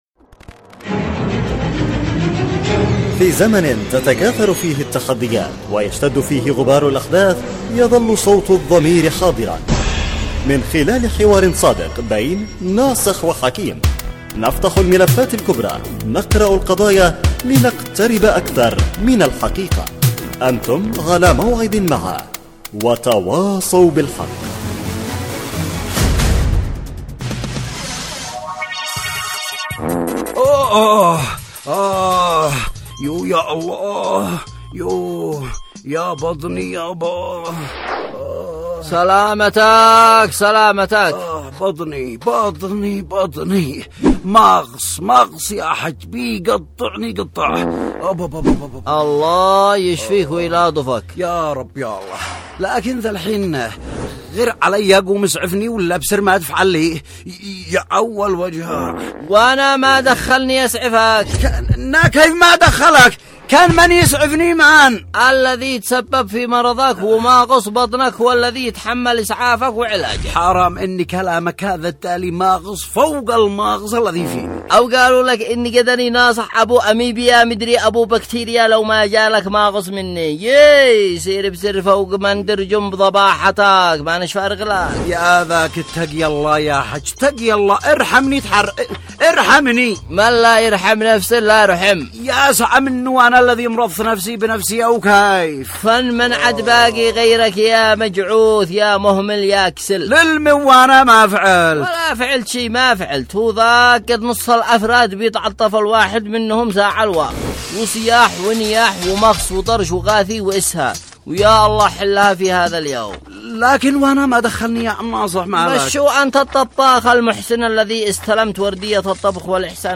وتواصوا بالحق، برنامج إذاعي درامي يعمل كل يوم على طرح إشكالية و مناقشة مشكلة تهم الجميع وبعد جدال بين الطرفين يتم الاحتكام بينهم الى العودة الى مقطوعة للسيد القائد تعالج المشكلة